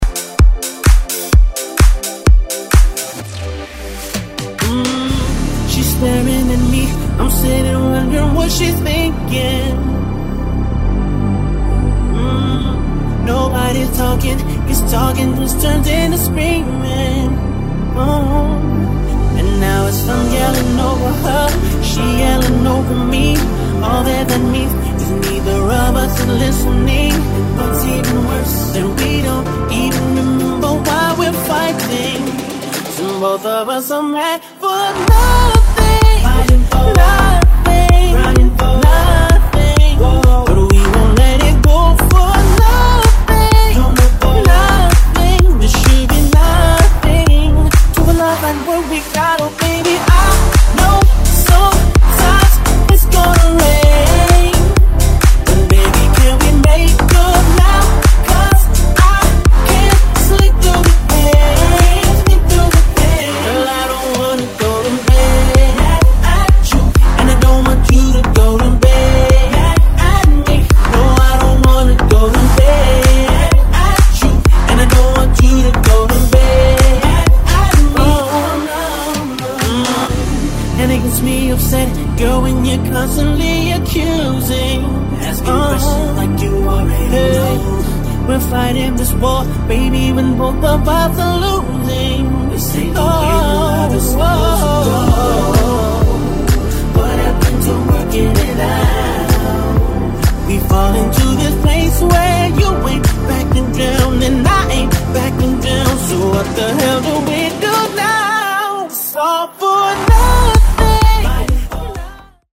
Genre: BOOTLEG
Clean BPM: 128 Time